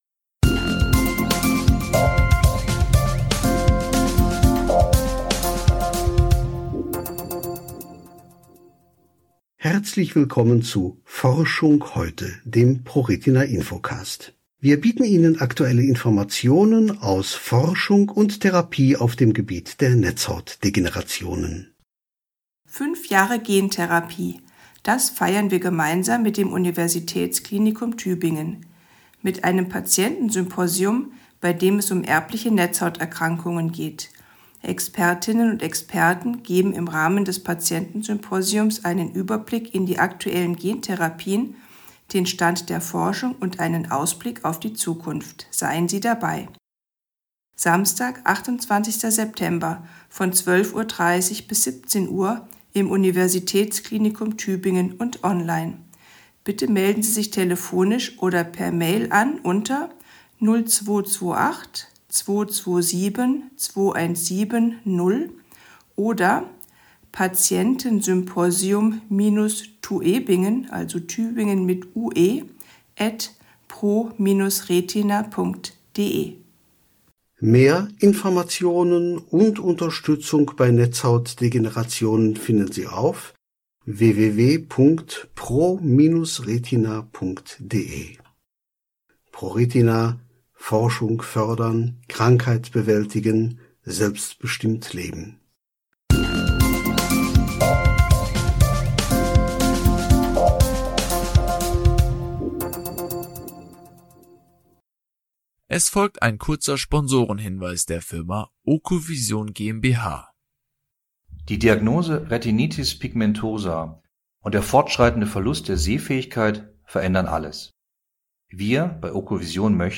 Hören Sie ein informatives Interview mit einer Redakteurin und hören Sie hinter die Kulissen.